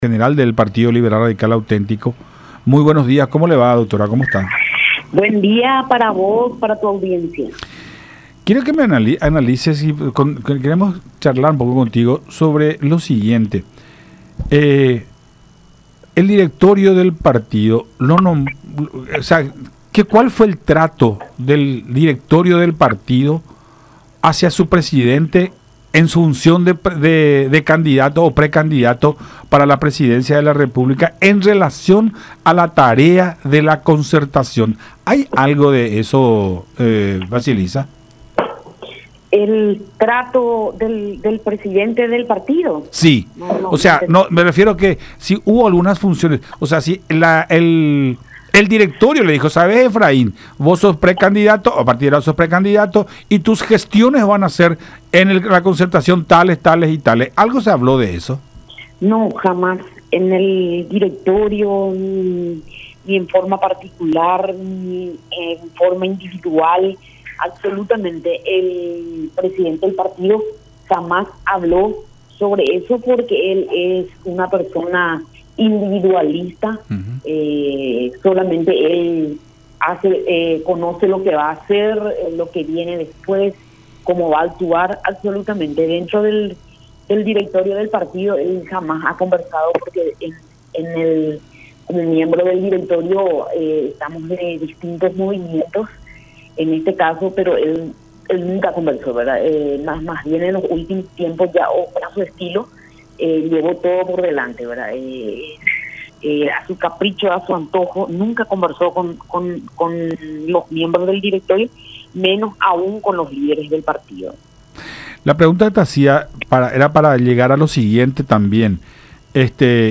en charla con Nuestra Mañana a través de Unión TV y radio La Unión